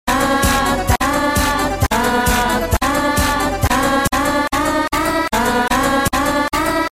música infantil sound effects free download